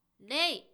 ダウンロード 女性_「気をつけ」
女性挨拶